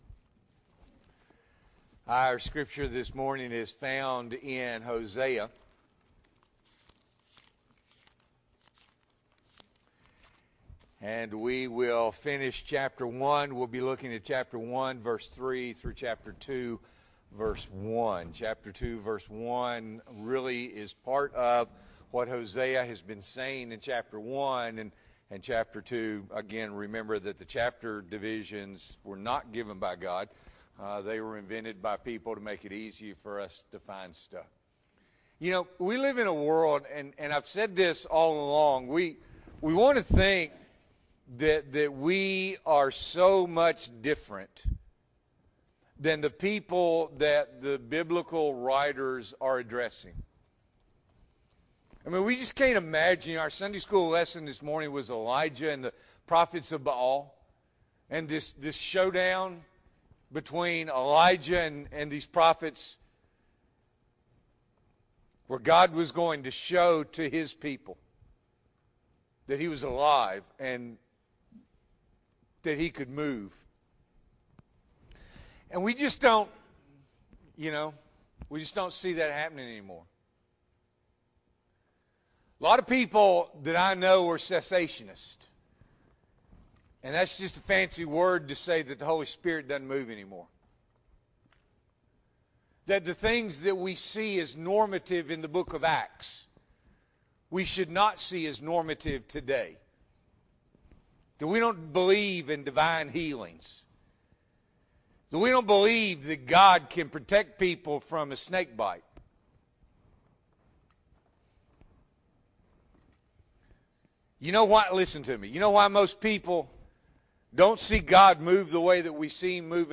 July 10, 2022 – Morning Worship